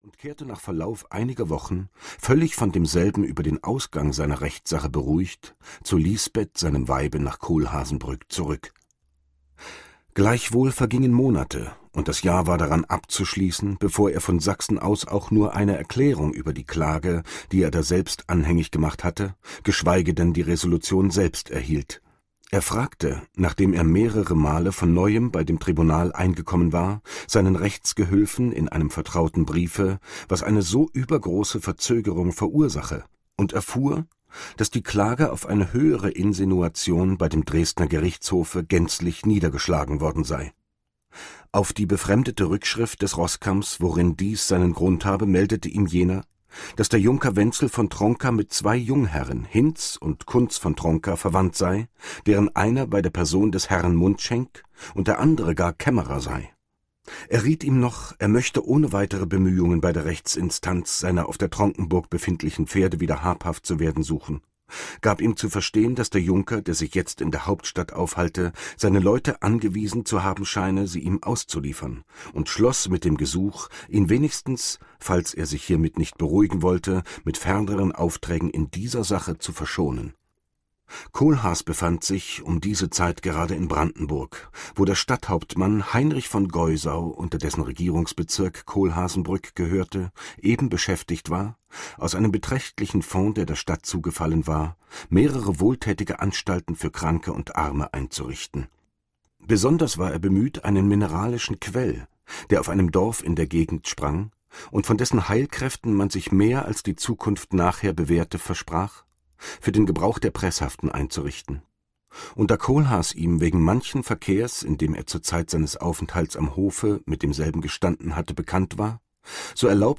Michael Kohlhaas - Heinrich von Kleist - Hörbuch